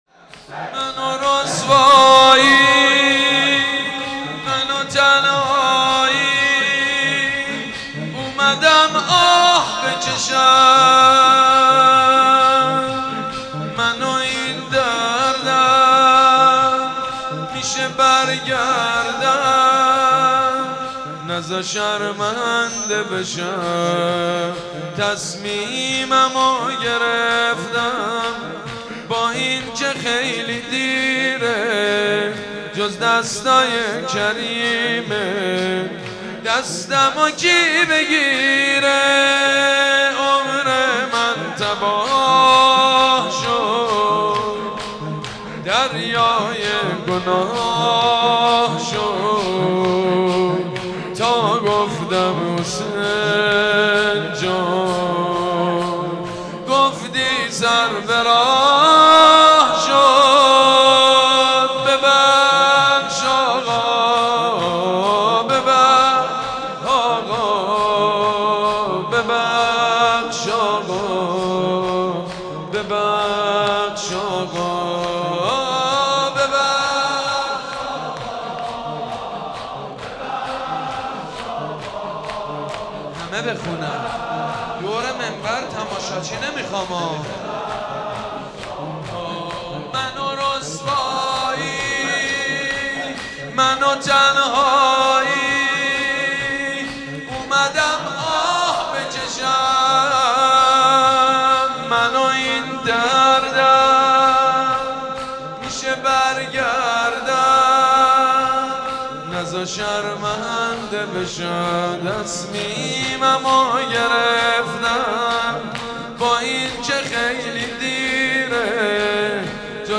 مداحی و نوحه
(شور)